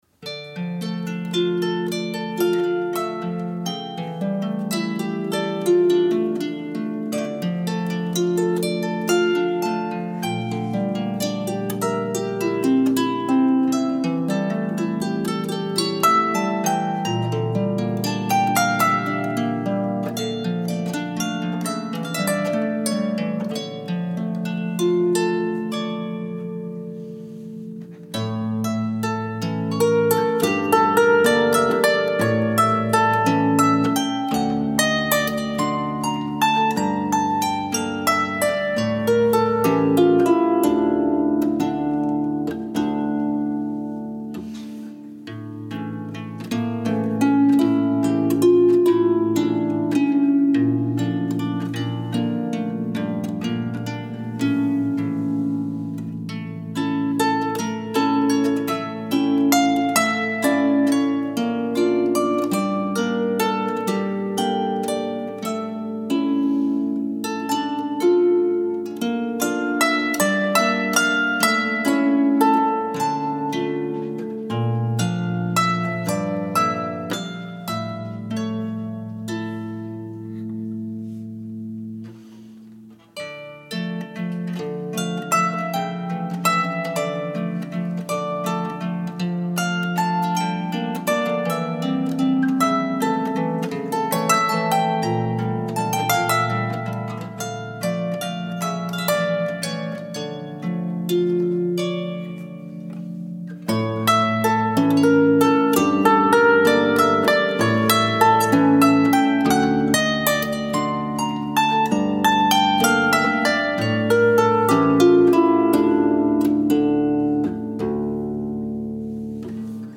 Voyage en Harpe est un récital de harpe.
Odom's Nechome - musique traditionnelle Klezmer